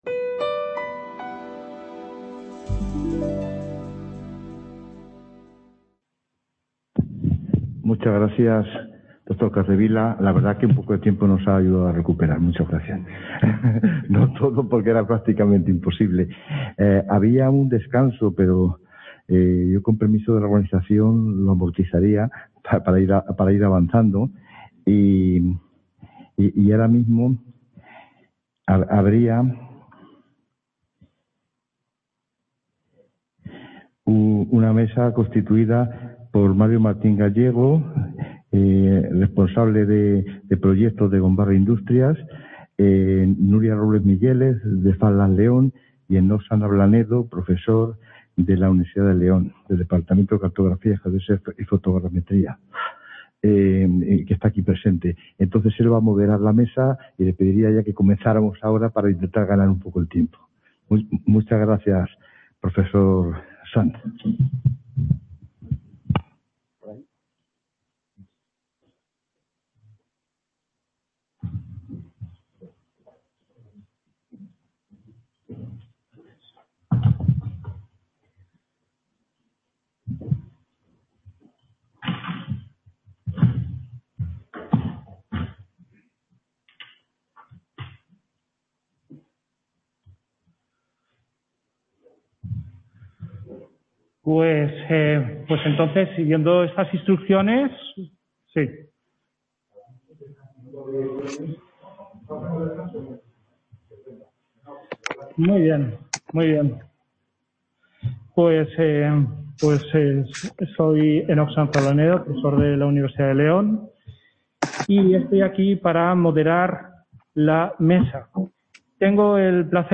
La Fabricación Aditiva se ha convertido en uno de los iconos de la Industrial 4.0 CA Ponferrada - Inauguración del Curso Académico 2020/2021 en el Centro Asociado a la UNED en Ponferrada.